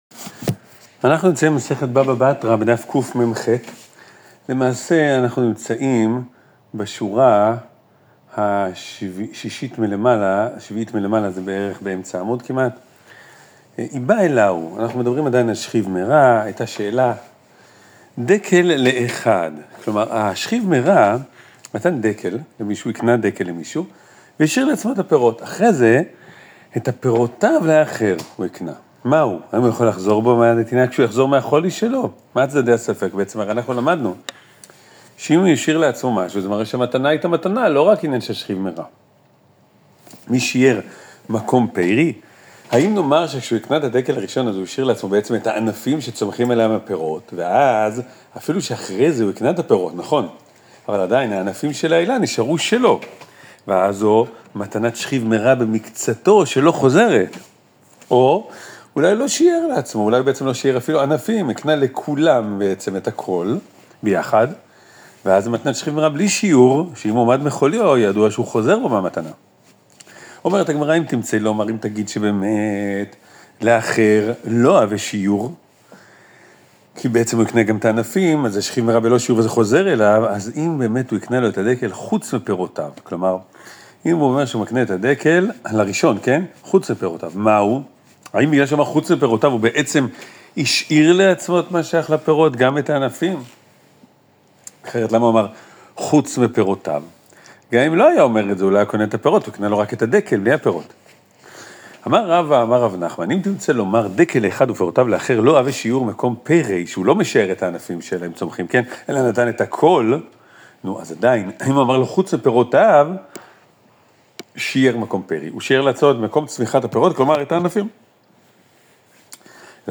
מגיד השיעור